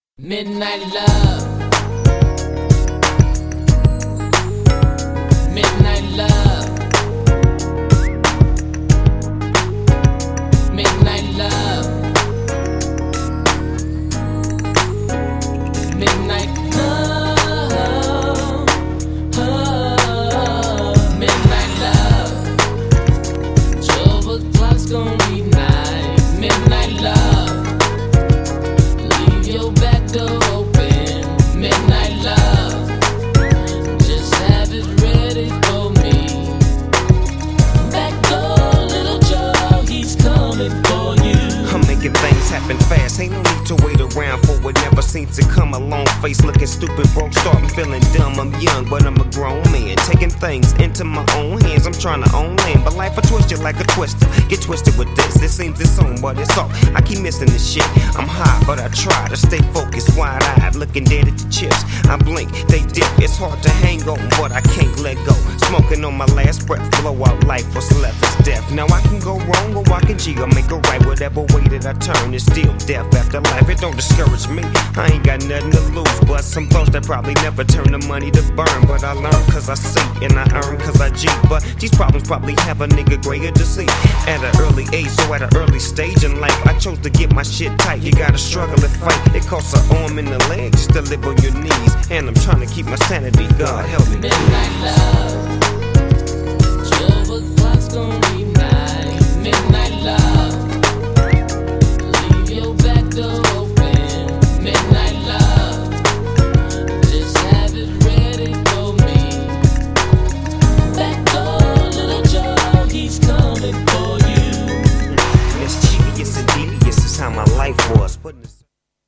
GENRE Hip Hop
BPM 91〜95BPM